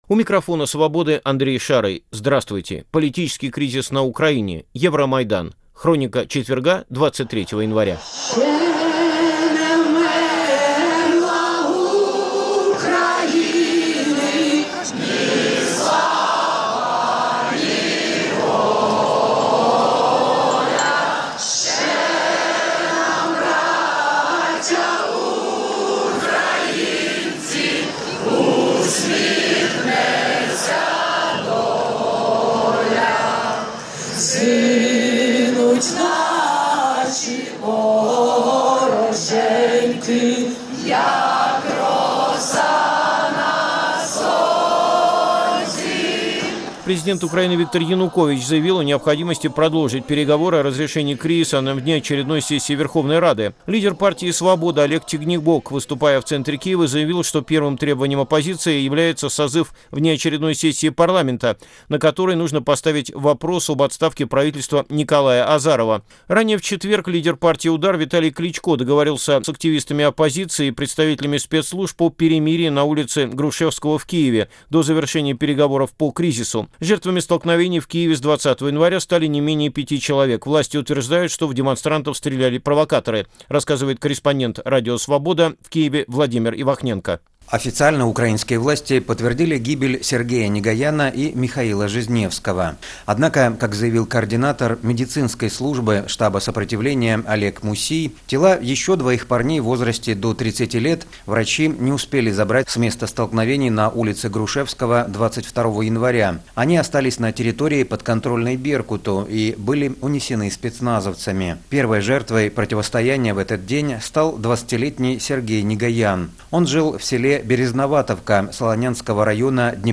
Интервью с Виталием Портниковым: причины бегства в Варшаву